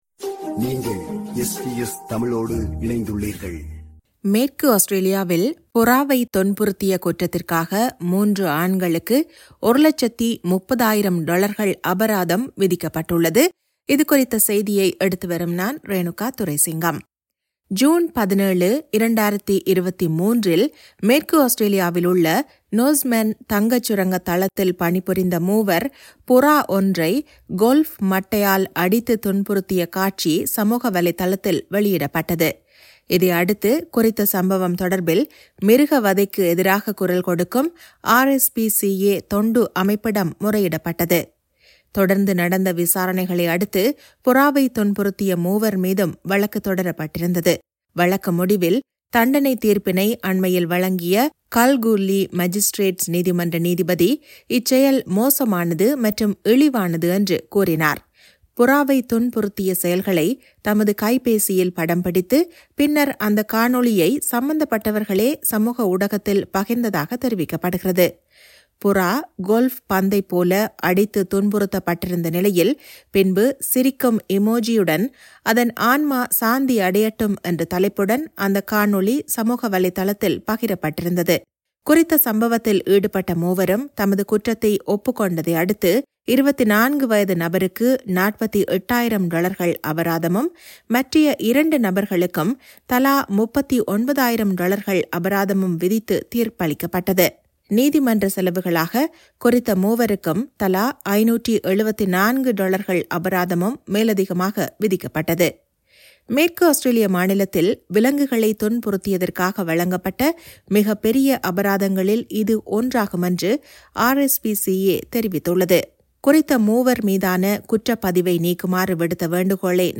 மேற்கு ஆஸ்திரேலியாவில், புறாவைத் துன்புறுத்திய குற்றத்திற்காக மூன்று ஆண்களுக்கு 130,000 டொலர்கள் அபராதம் விதிக்கப்பட்டுள்ளது. இதுகுறித்த செய்தியை எடுத்துவருகிறார்